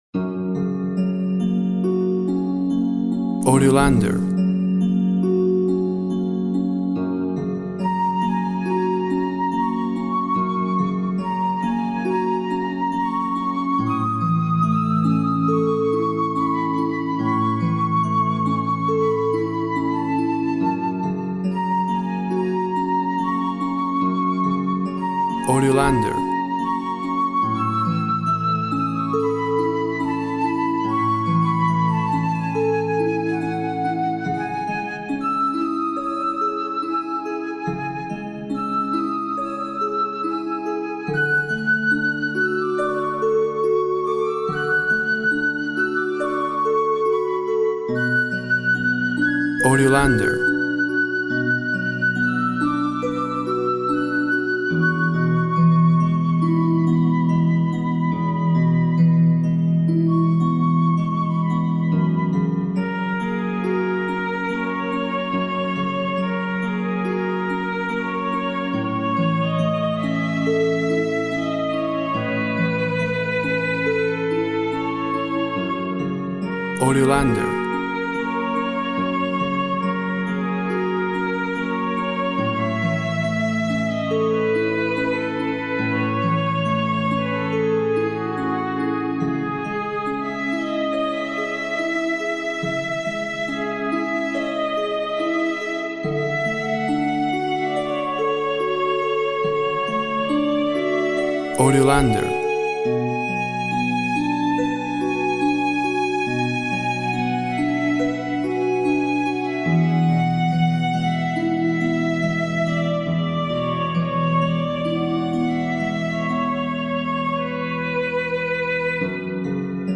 A harp accompanies a flute and violin in a love duet.
Tempo (BPM) 70/52